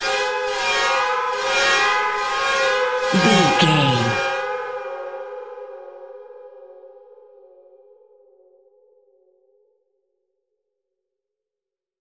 Sound Effects
In-crescendo
Atonal
ominous
dark
haunting
eerie
synth
keyboards
ambience
pads